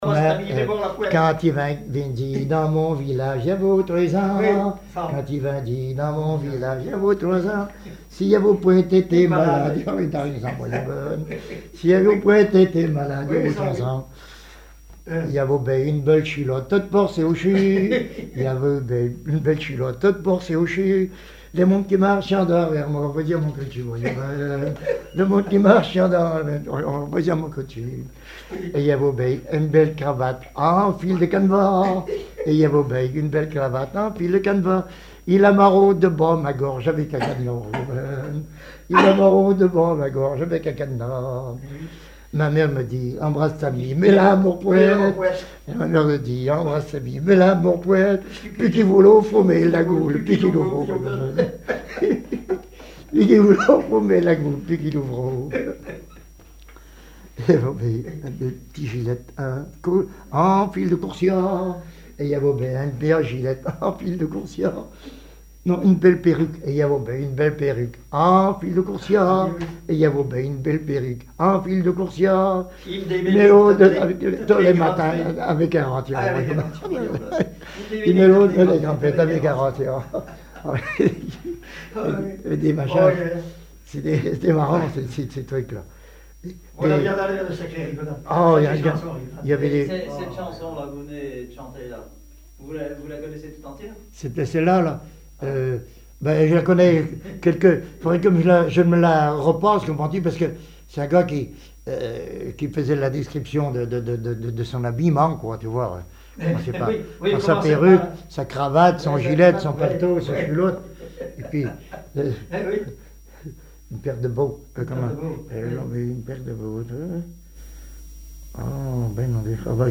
Genre strophique
Chansons populaires et témoignages
Pièce musicale inédite